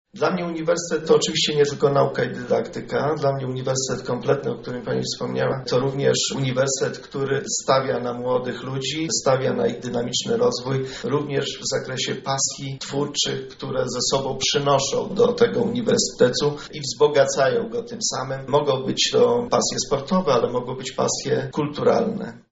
• Zarządzanie uniwersytetem to wspieranie członków wspólnoty akademickiej we wszystkich sferach ich aktywności – mówi profesor Radosław Dobrowolski, rektor Uniwersytetu Marii Curie-Skłodowskiej.